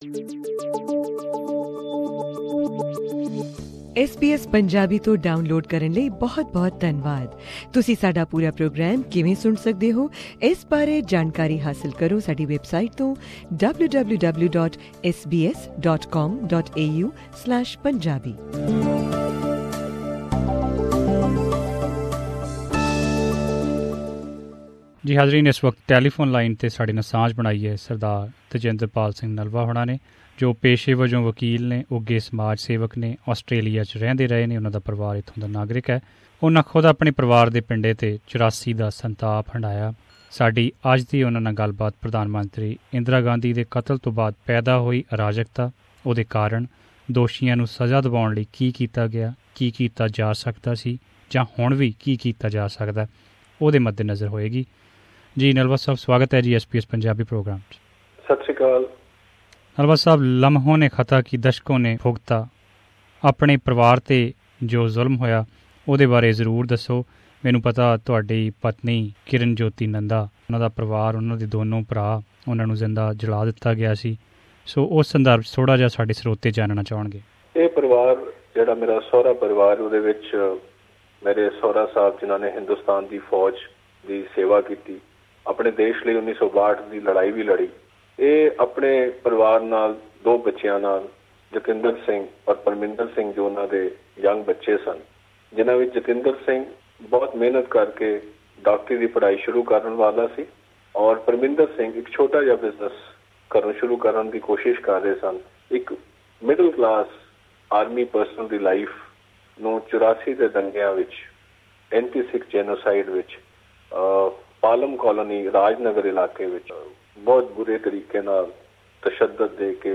On telephone line